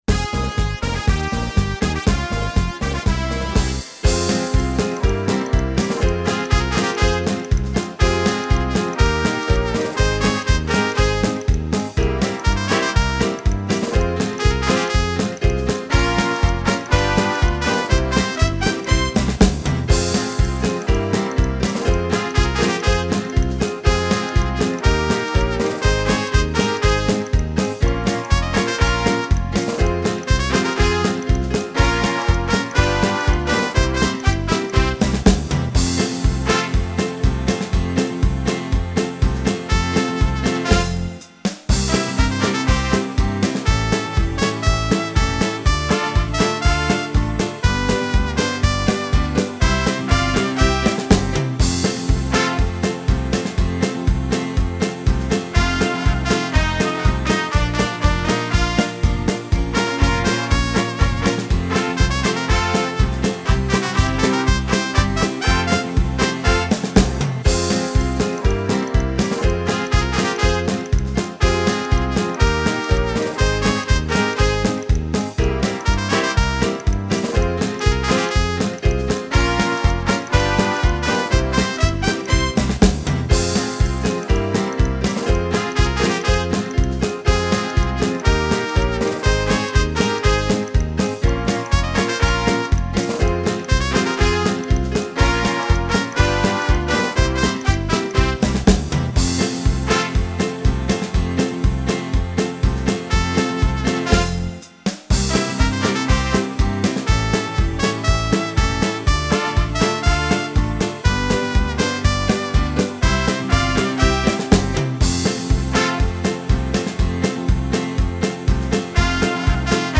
Paso doble